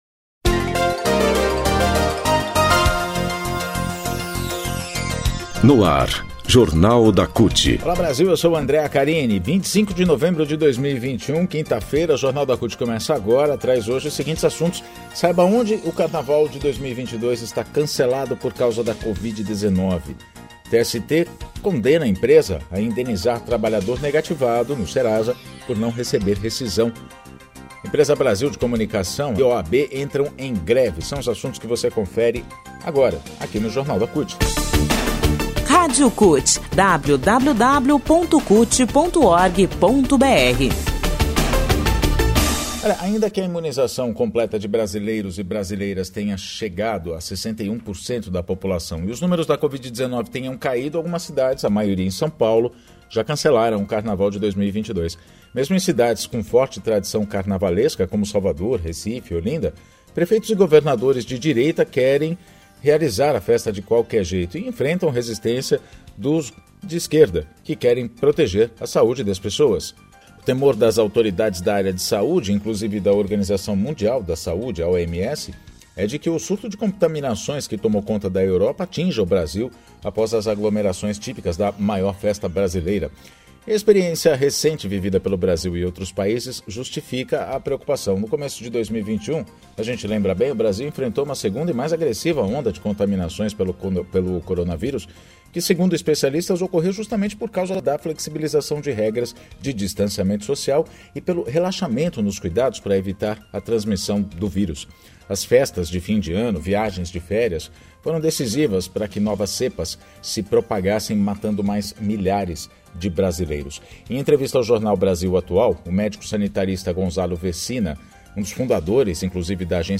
Jornal de rádio da CUT - quinta-feira, 25 de novembro de 2021